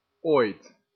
Ääntäminen
IPA: /oːi̯t/